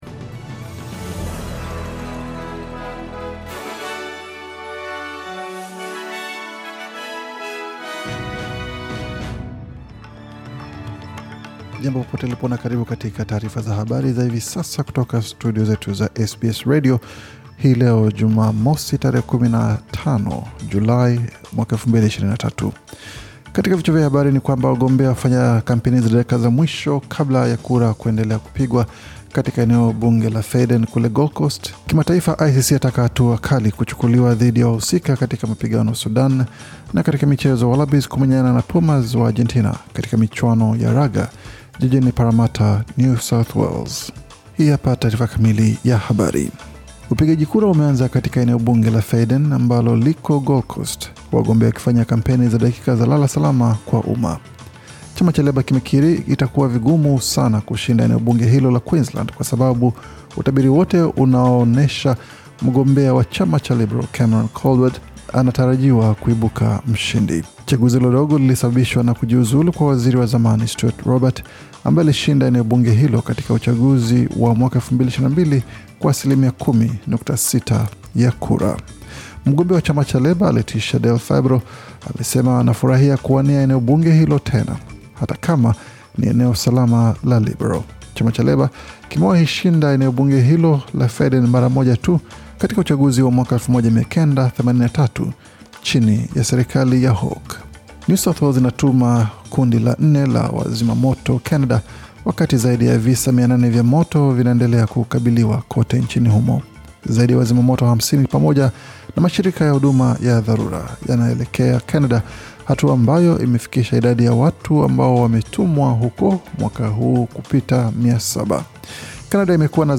Taarifa ya Habari 15 Julai 2023